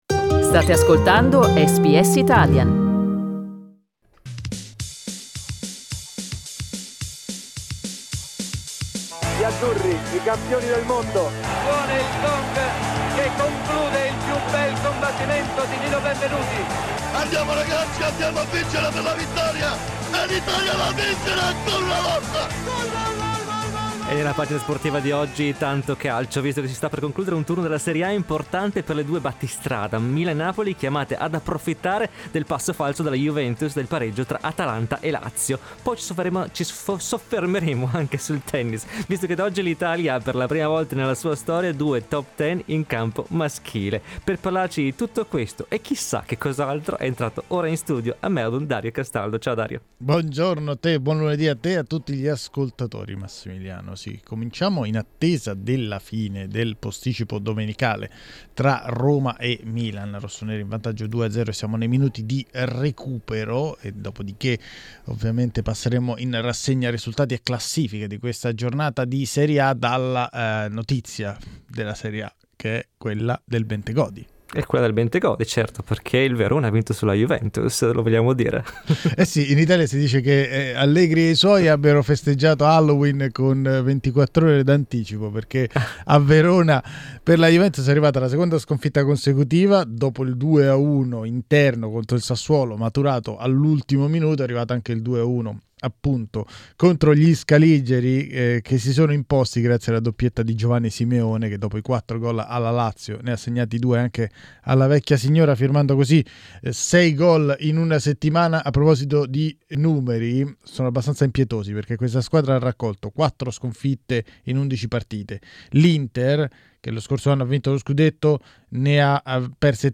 Le parole dei protagonisti del fine settimana sportivo.